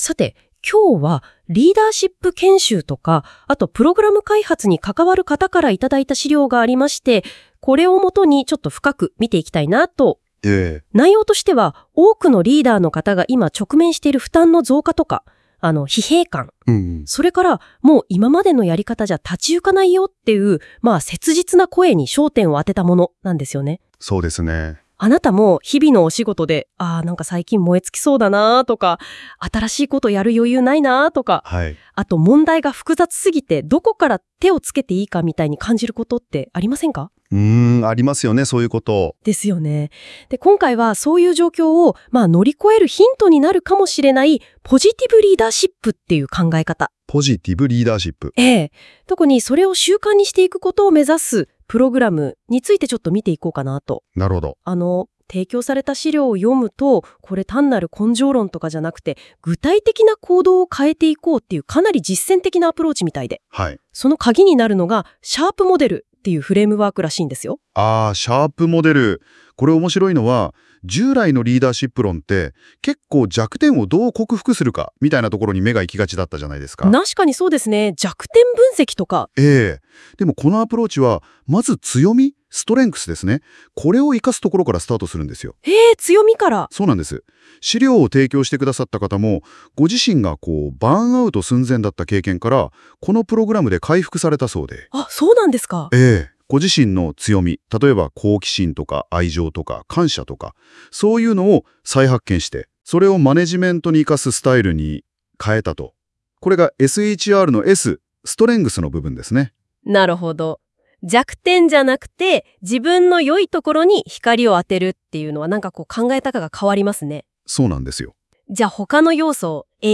対談風に要約されたAI音声（7分）：忙しい方にもおすすめのダイジェスト版